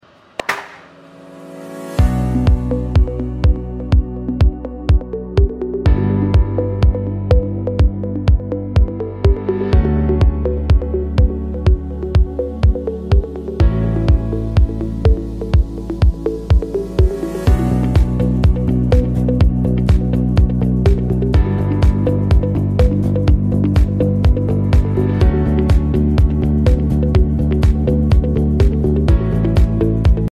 DDD Keyboard Graffiti Keycap on sound effects free download
DDD Keyboard Graffiti Keycap on Keychron Q5 Pro wireless mechanical keyboard!